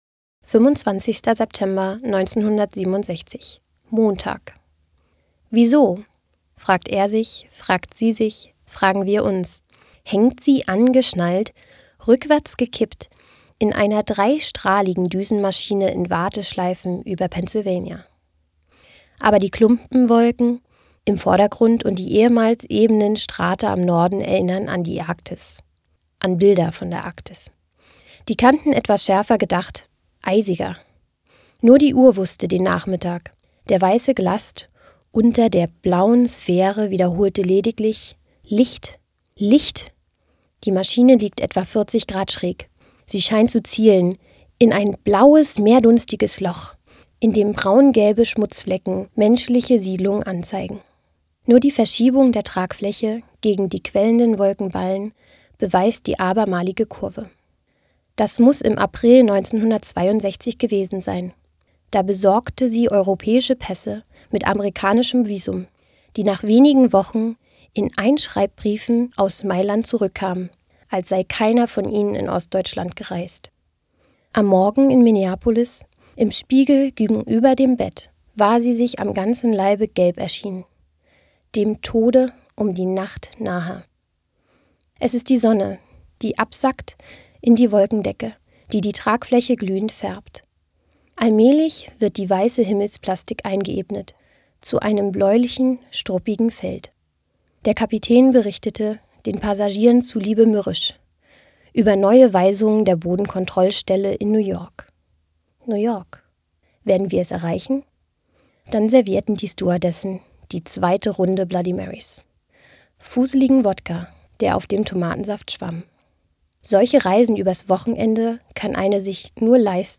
Eine Stadt liest Uwe Johnsons Jahrestage - 25.